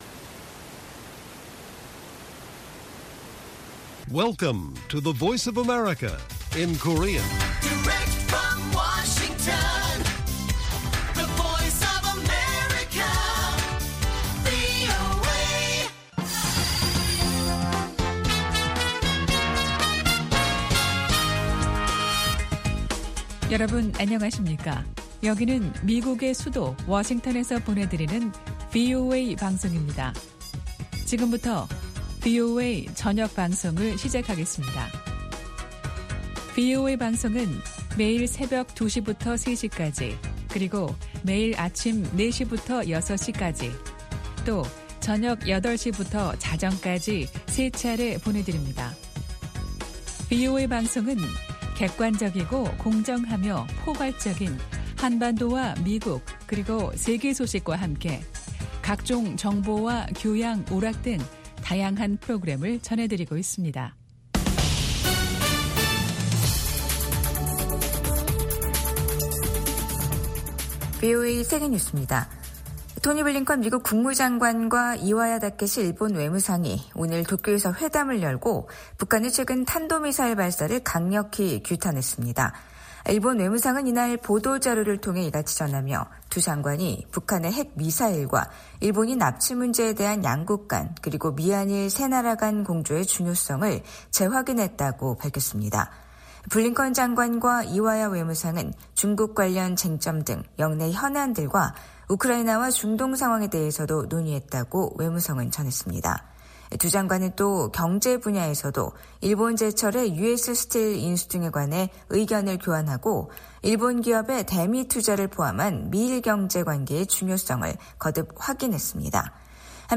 VOA 한국어 간판 뉴스 프로그램 '뉴스 투데이', 2025년 1월 7일 1부 방송입니다. 북한은 어제(6일) 신형 극초음속 중장거리 탄도미사일 시험발사에 성공했다며 누구도 대응할 수 없는 무기체계라고 주장했습니다. 미국과 한국의 외교장관이 북한의 탄도미사일 발사를 강력히 규탄했습니다.